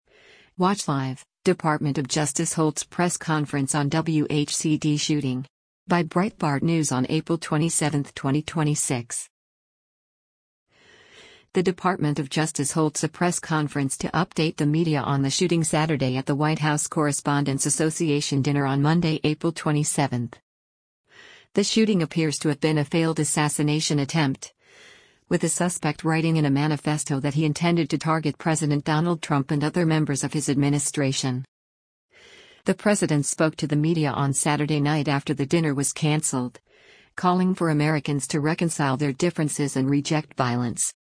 The Department of Justice holds a press conference to update the media on the shooting Saturday at the White House Correspondents’ Association Dinner on Monday, April 27.